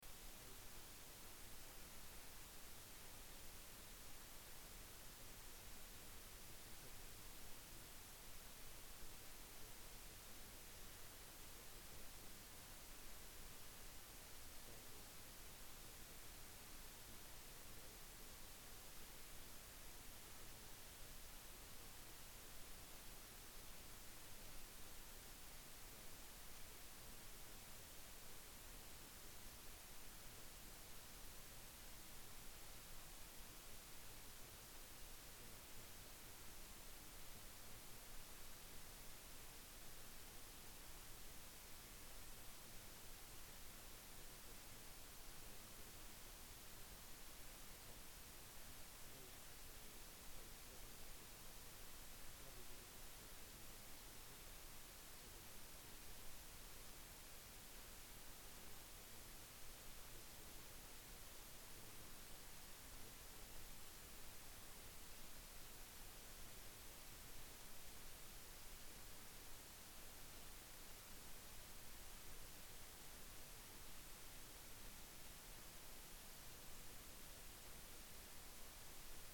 Przemawia członek zarządu woj. mazowieckiego p. Wiesław Raboszuk -